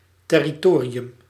Ääntäminen
Synonyymit zone bailiwick turfdom Ääntäminen US US : IPA : /tɛrɪtɔri/ UK : IPA : /tɛrɪt(ə)ri/ Lyhenteet ja supistumat (laki) Terr.